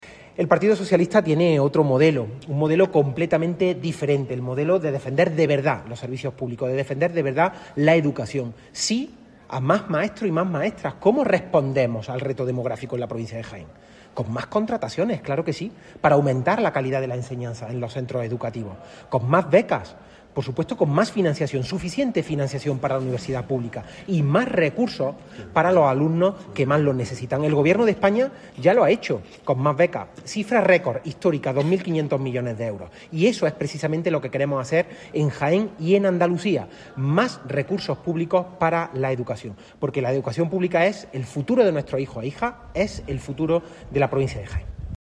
De la Rosa, que ha participado en un Foro sobre Educación organizado por el PSOE de Jaén en la ciudad de Úbeda, manifestó que la educación construye sociedad y que hay dos modelos: una educación “para unir” y otra “para segregar”.